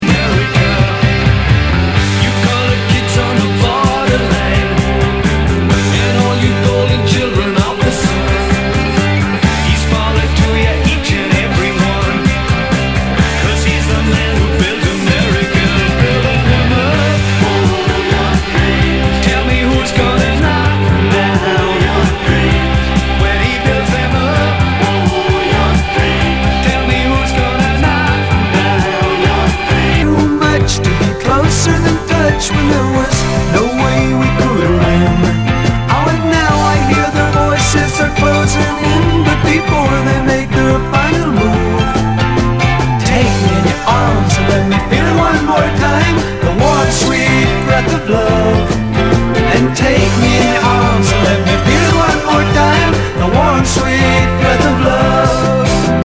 ROCK/POPS/INDIE
ナイス！フォークロック！！
全体にチリノイズが入ります